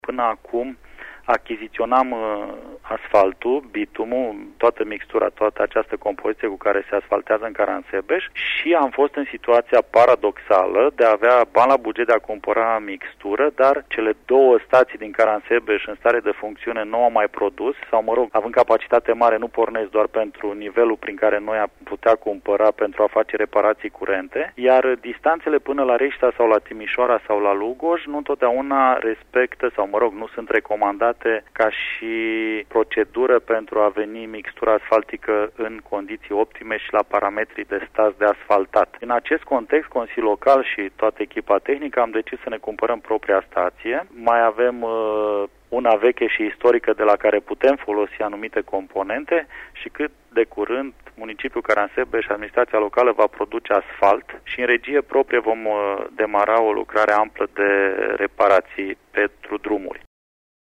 Primarul Caransebeşului, Marcel Vela: